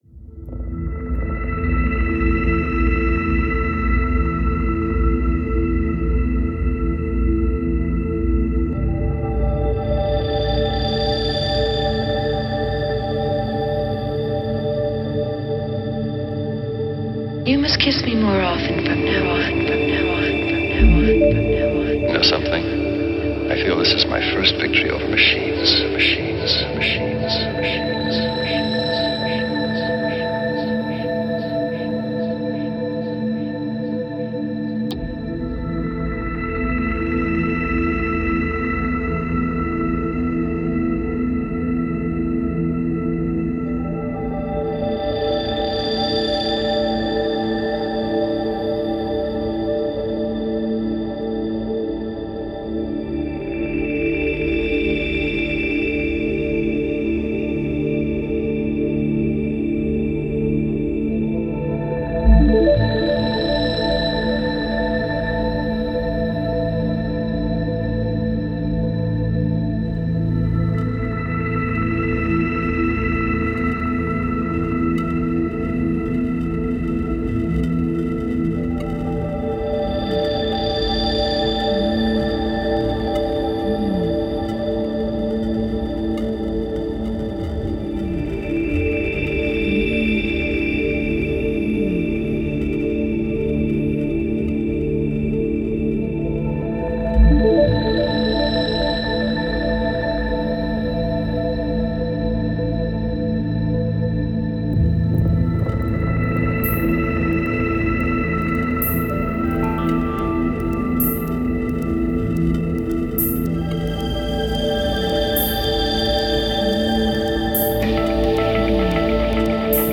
Genre: Electro.